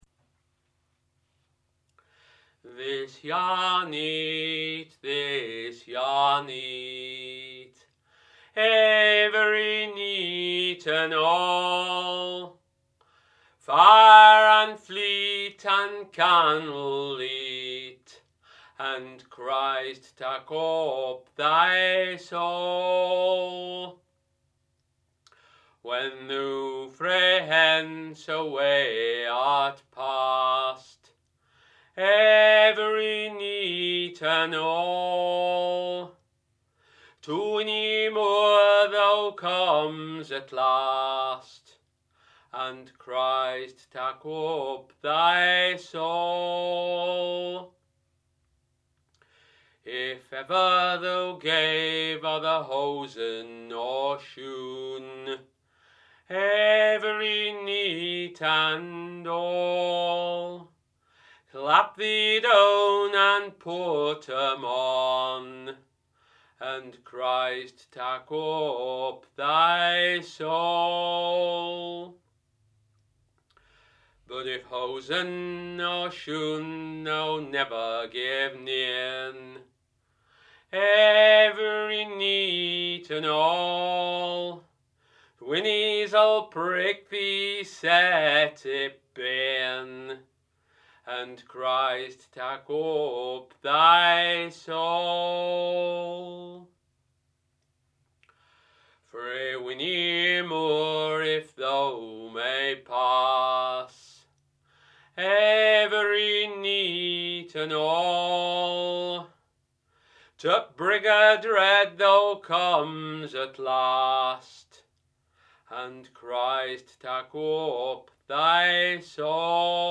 Harrogate
Ritual
F# Aeolian